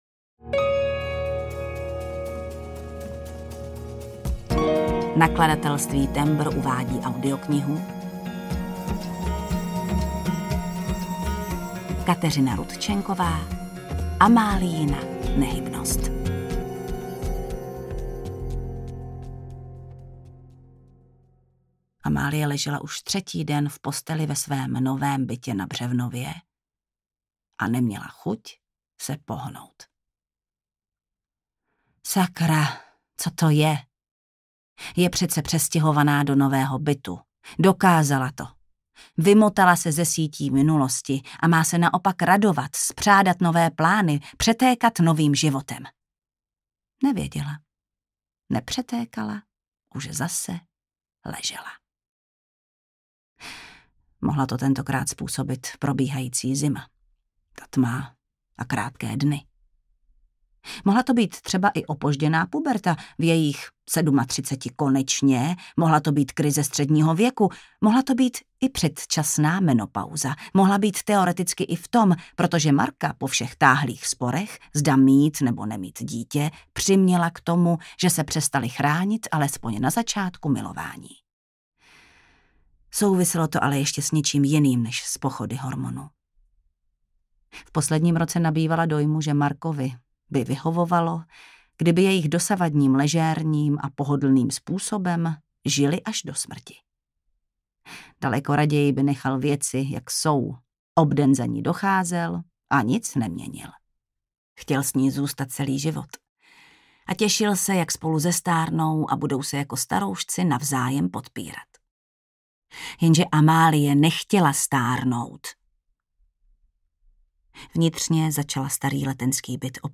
Amáliina nehybnost audiokniha
Ukázka z knihy
• InterpretJana Stryková